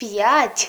Tones bugfix, numbers station voice files search